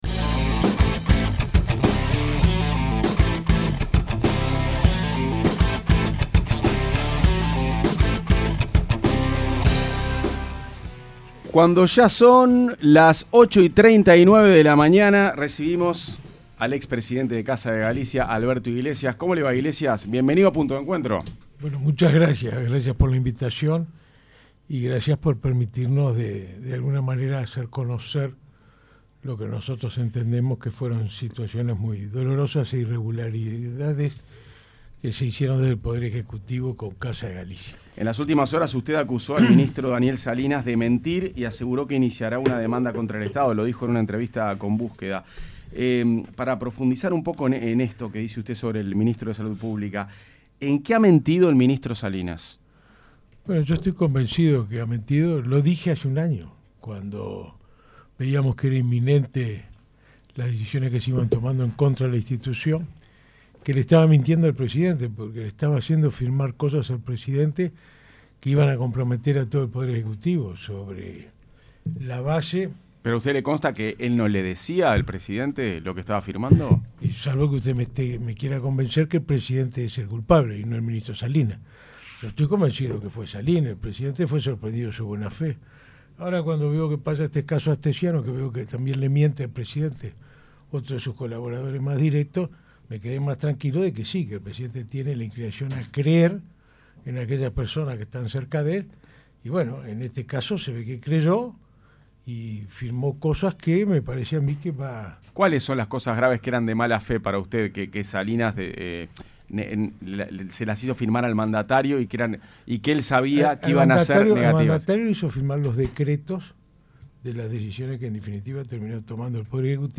En entrevista en Punto de Encuentro en 970 Universal, dijo que está convencido que el ministro le mintió al presidente, Luis Lacalle Pou, haciéndole firmar documentos que comprometieron a todo el Poder Ejecutivo.